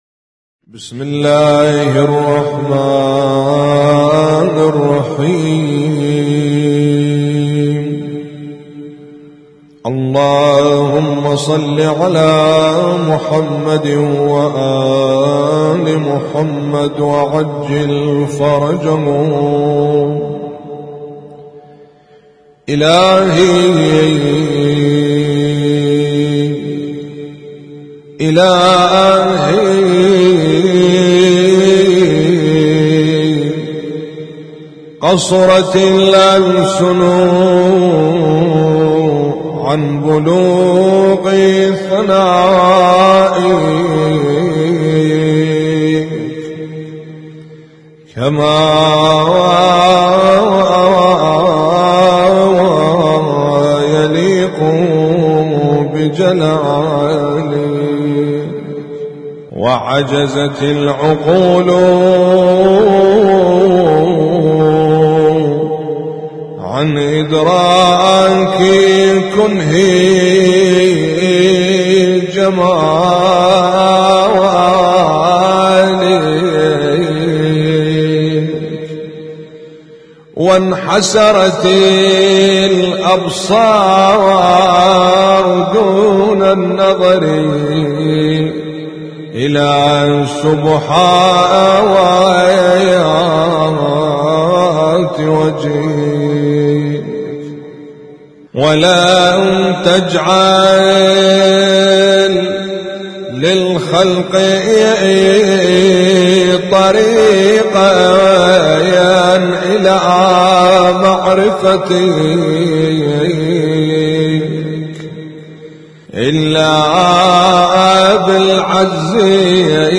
اسم التصنيف: المـكتبة الصــوتيه >> الصحيفة السجادية >> المناجاة